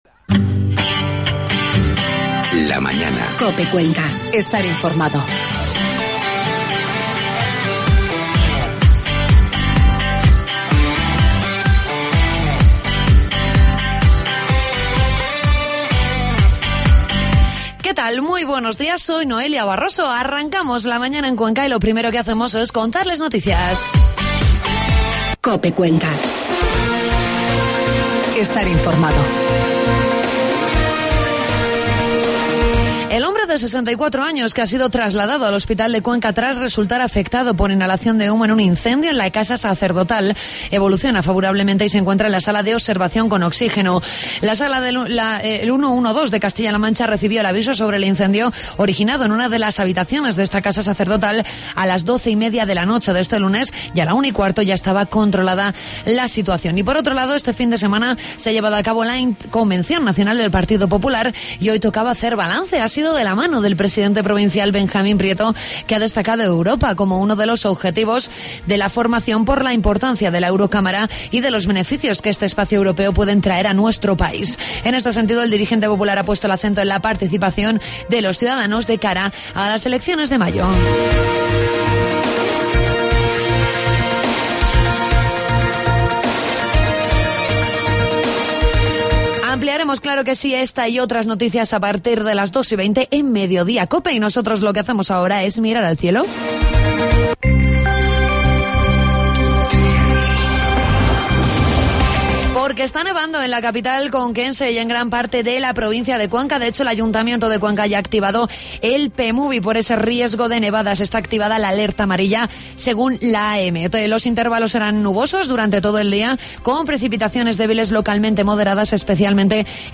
Entrevistamos al vicepresidente de la Insistitución Provincia, Julián Huete, con el que hablamos de la situación de las trabajadoras de la residencia provincial Sagrado Corazón de Jesús.